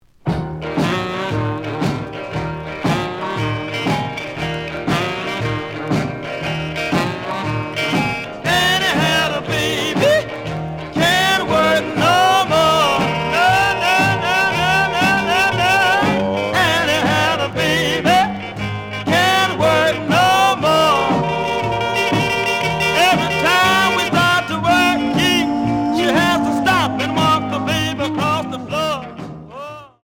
The audio sample is recorded from the actual item.
●Genre: Rhythm And Blues / Rock 'n' Roll
Slight edge warp. But doesn't affect playing.